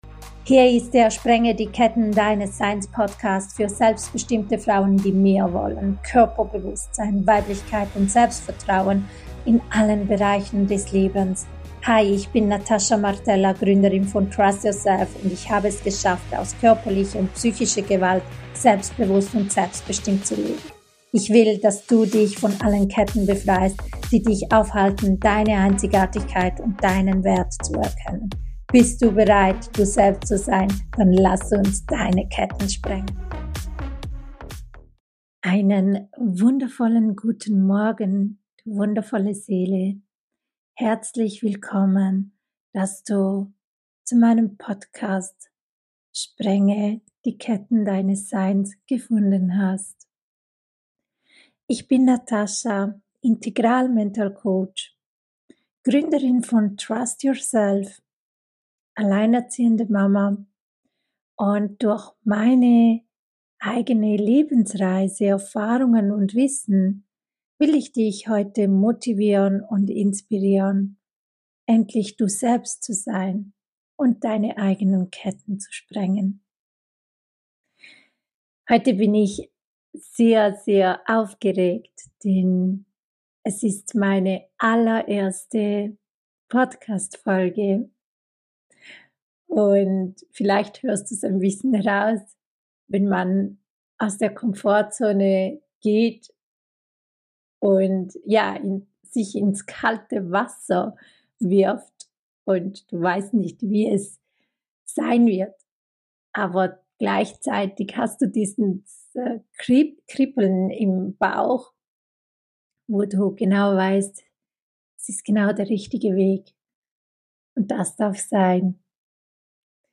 Ich spreche hier frei, ohne Script und direkt aus dem Herzen.